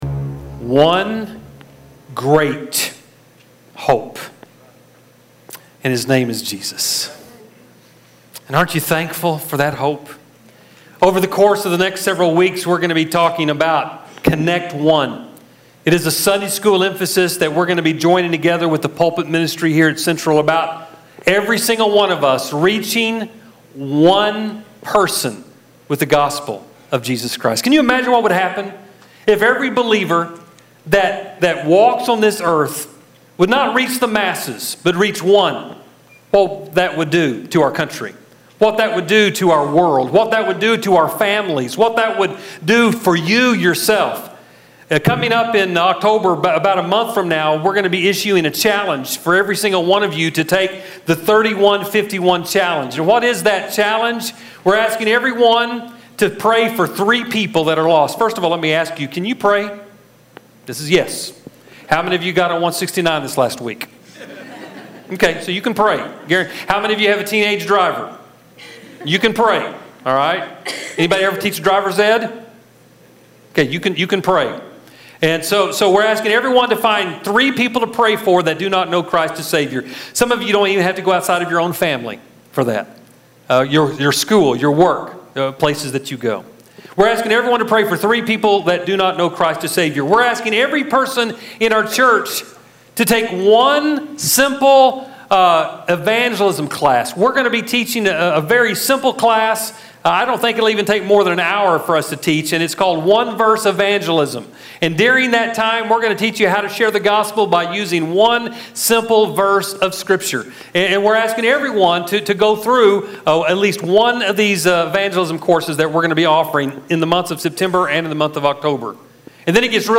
09-4-16-Sermon.mp3